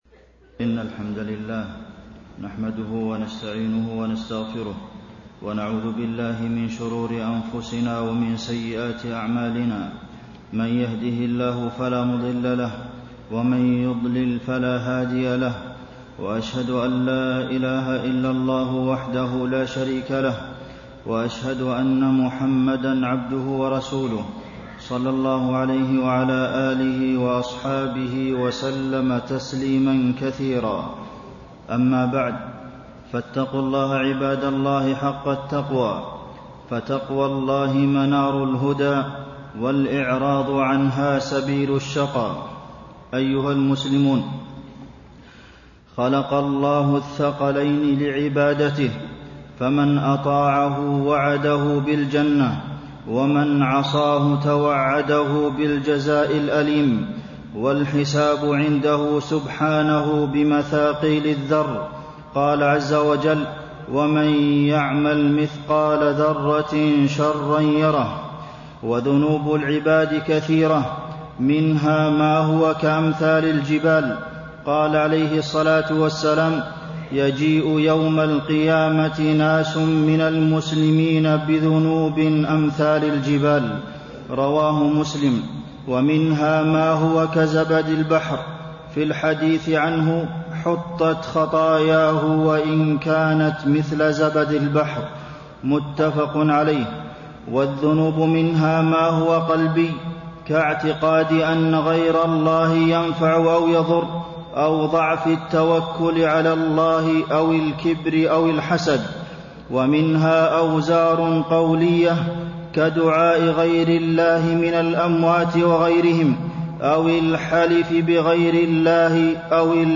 تاريخ النشر ٢٣ محرم ١٤٣٤ هـ المكان: المسجد النبوي الشيخ: فضيلة الشيخ د. عبدالمحسن بن محمد القاسم فضيلة الشيخ د. عبدالمحسن بن محمد القاسم مغفرة الذنوب بيسير القول والعمل